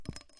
打击性的声音 " 小刀枕头踢2
描述：打击乐的刀子
Tag: 冲击刀 打击乐器 金属 银器 金属 随机 器皿 声音